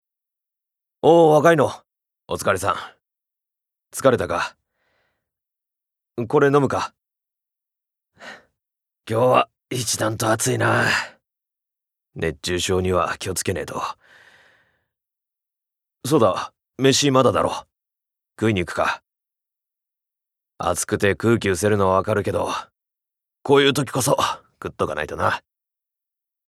Voice Sample
ボイスサンプル
セリフ６